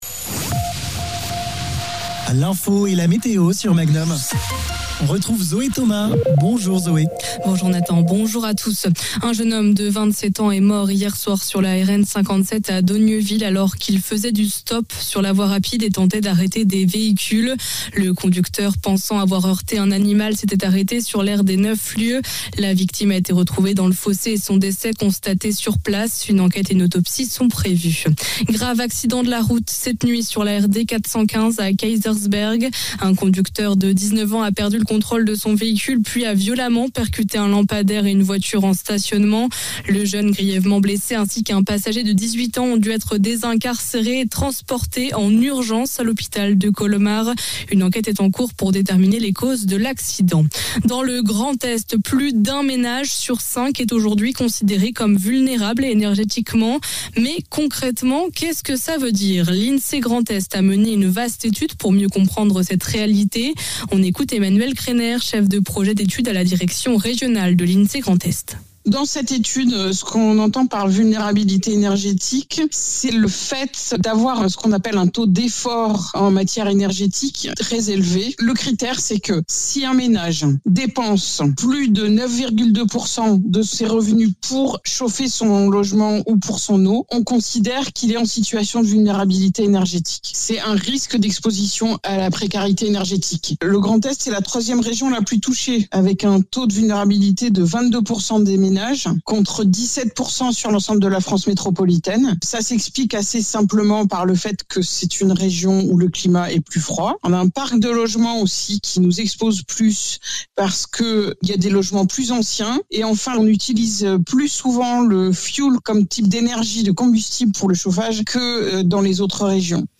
flash information